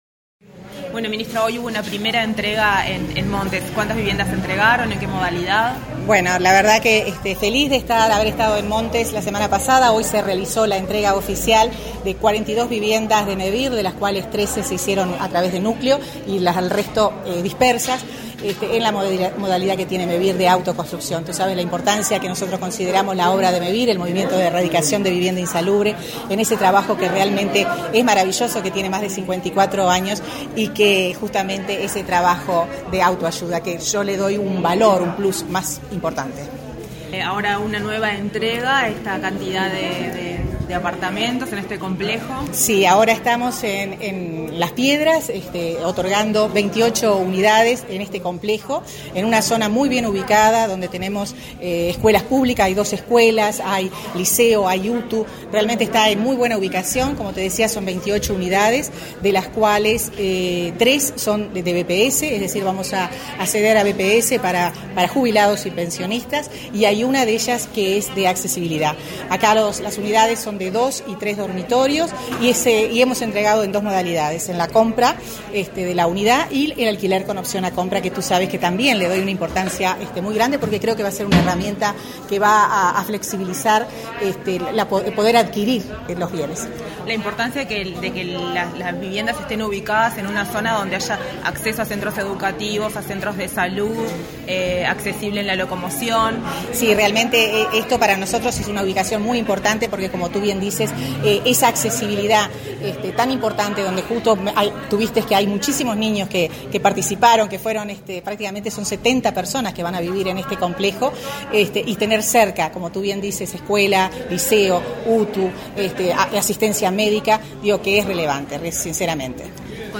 Declaraciones de la ministra de Vivienda, Irene Moreira
Tras entregar llaves a los propietarios de las viviendas inauguradas en las localidades de Montes y Las Piedras, este 25 de noviembre, la ministra
moreira prensa.mp3